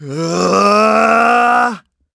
Riheet-Vox_Casting2.wav